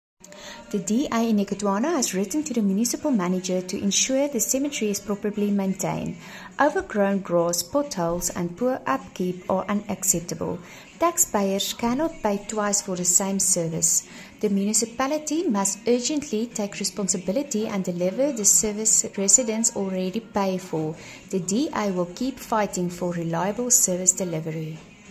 Afrikaans soundbites by Cllr Anelia Smit and